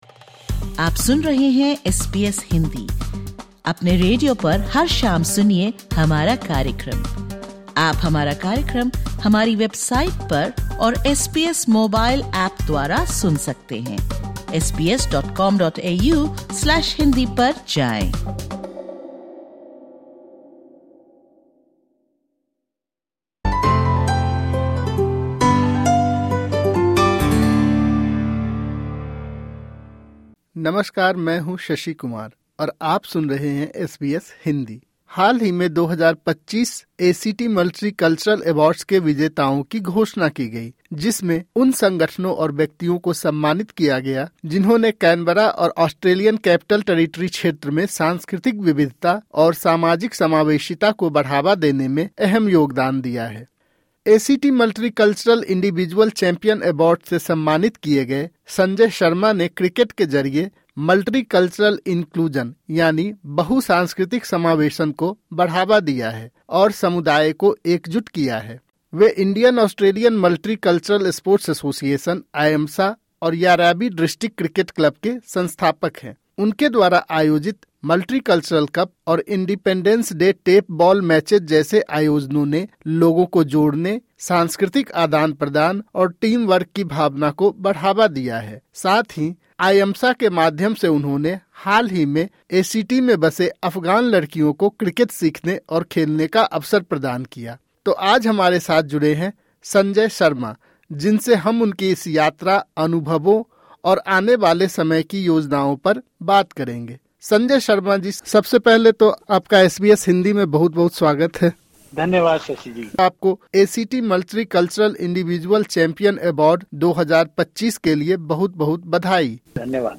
सुनिए एसबीएस हिन्दी की उनसे की गई यह बातचीत।